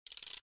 reload_gun.ogg